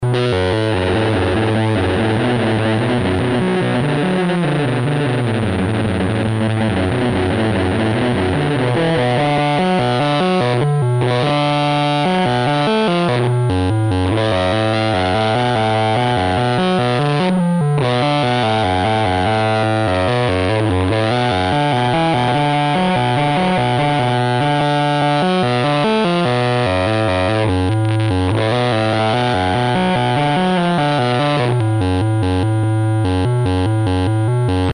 FET VCLPF Schematic
This makes the filter quite noisy because any noise also gets amplified.
mosfetsample1.mp3